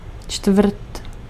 Ääntäminen
Synonyymit picollo quartier quatrième quarterback Ääntäminen : IPA: /kaʁ/ Haettu sana löytyi näillä lähdekielillä: ranska Käännös Ääninäyte Substantiivit 1. čtvrt 2. čtvrtina 3. čtvrtka {f} Suku: m .